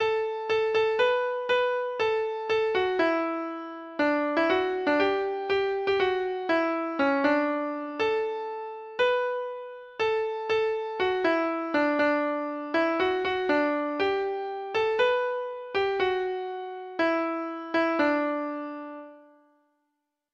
Folk Songs from 'Digital Tradition' Letter F Fire Down Below
Treble Clef Instrument  (View more Intermediate Treble Clef Instrument Music)
Traditional (View more Traditional Treble Clef Instrument Music)